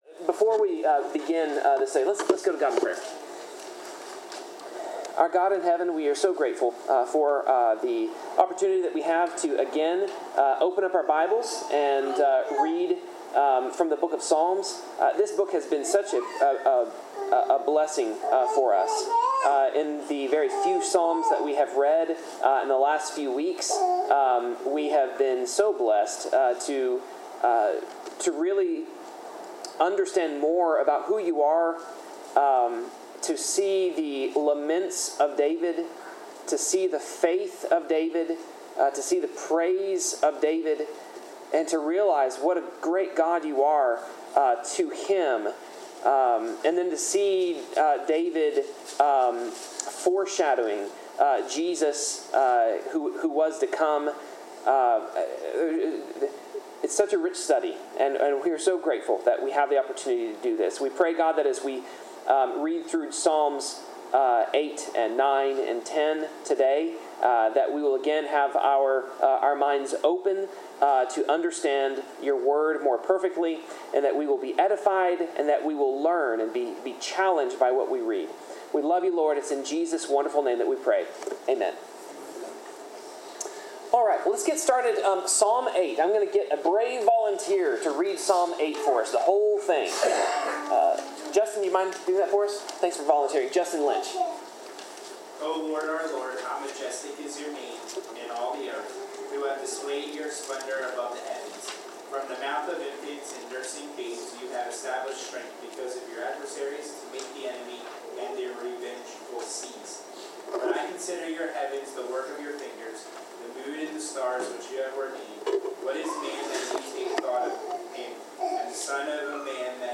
Bible class: Psalms 8-10
Service Type: Bible Class